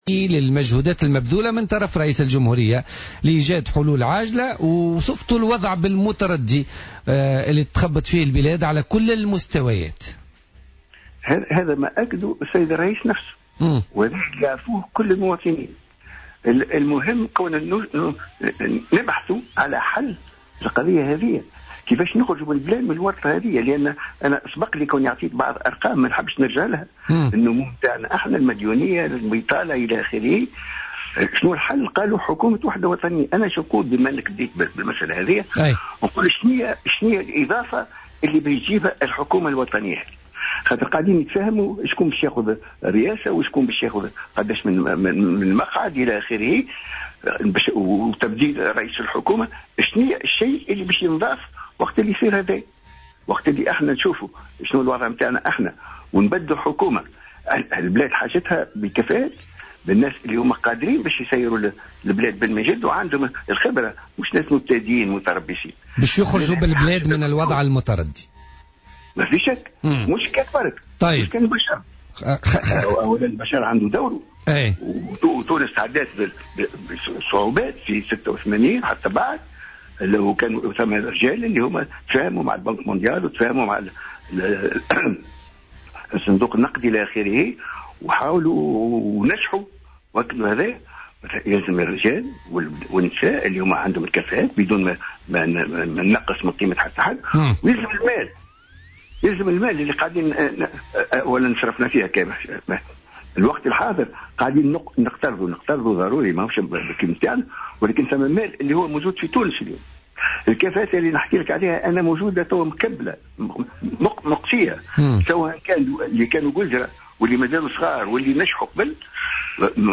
وقال في تعليقه على مبادرة رئيس الجمهورية الباجي قايد السبسي لتشكيل حكومة وحدة وطنية في برنامج "الحدث" بـ "الجوهرة أف أم" إنه من الضروري تجنيد كفاءات حقيقية في حكومة الوحدة الوطنية بعد إقصاءها مع رجال الأعمال الذين بقوا في حالة تردد بسبب عدم إصدار قانون للمصالحة الوطنية.